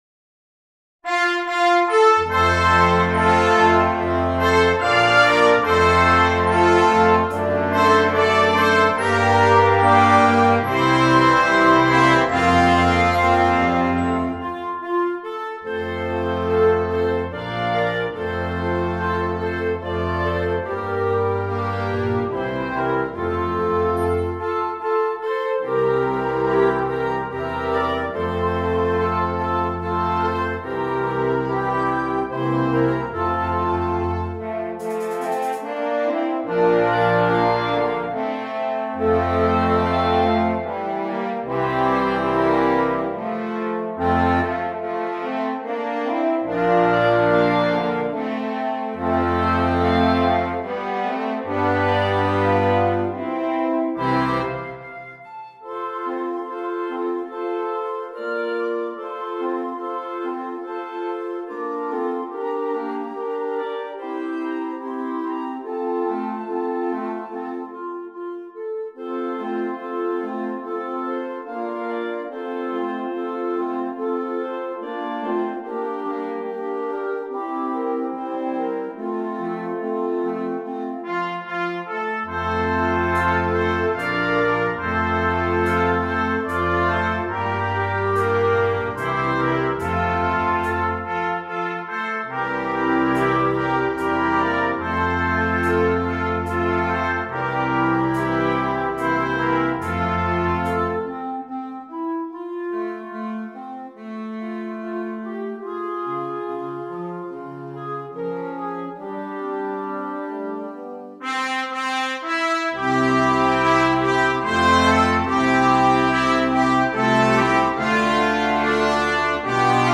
2. Concert Band
Full Band
without solo instrument
Christmas Music